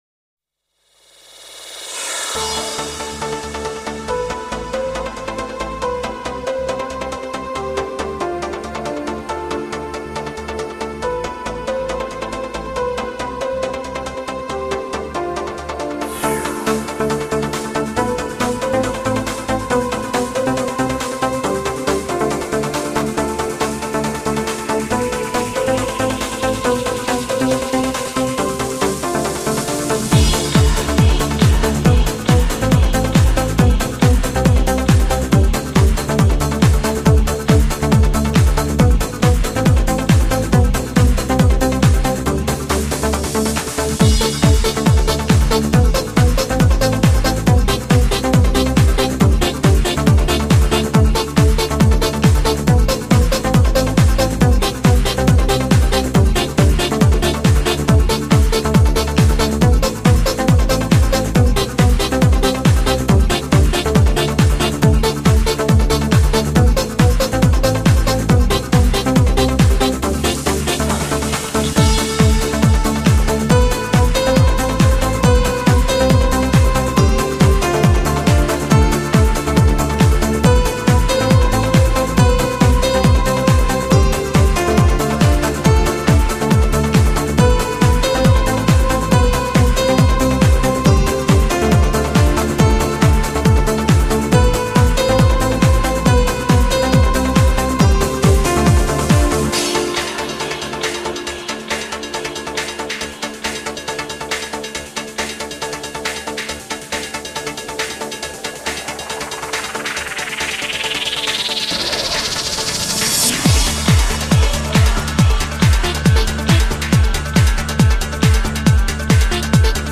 迷幻舞曲
TRANCE 狂飙电音横扫全球